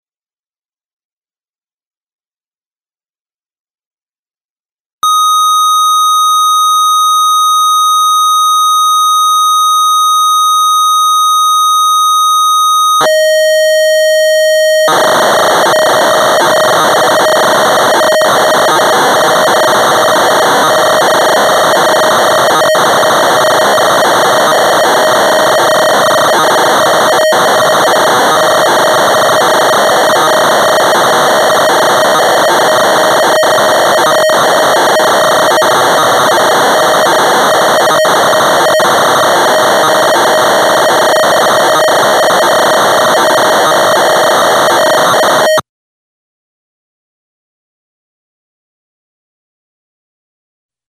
In my application, I sent the data signal into my recording console and recorded it into CoolEdit Pro.
If you would want to see and try a typical internal cart dump of patches from V3.5, here it is in MP3 format:
The first portion of the wave file is the header. This constant data stream allows the ESQ1 to sync with the incoming data preparing it to record. The next portion is the actual data being loaded into the internal cart location.